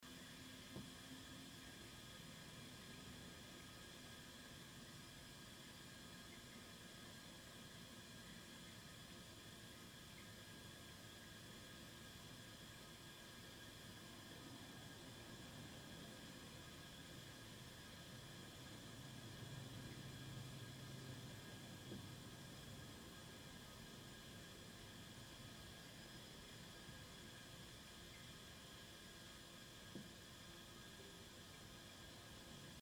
На этой странице собраны звуки работающего радиатора — от мягкого потрескивания до монотонного гула.
Шипение воды в радиаторе